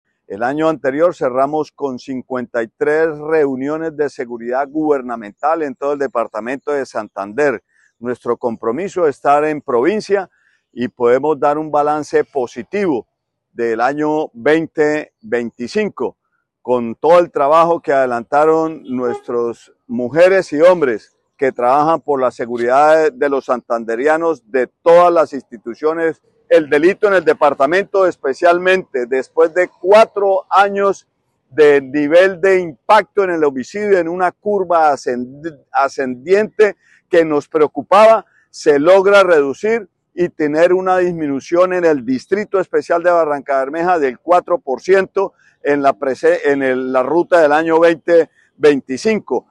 El secretario del Interior de Santander, Óscar Hernández